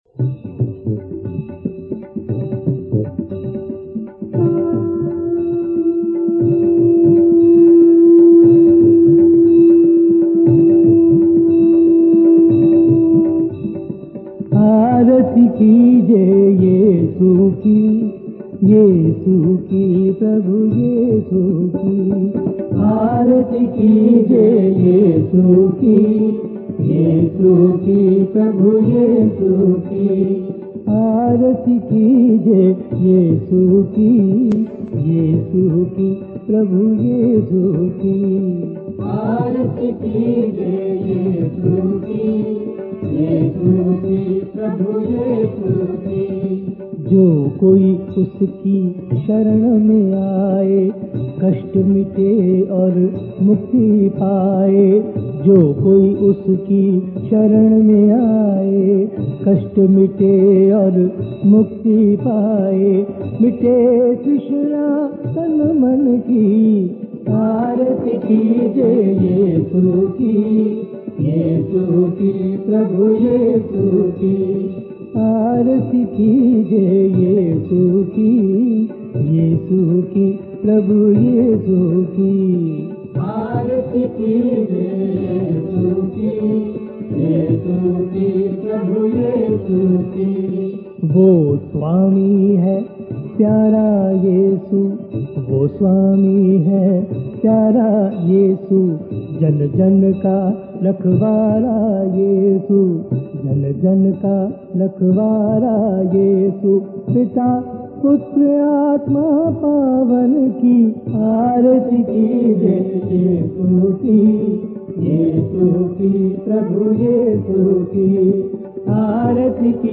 Hymns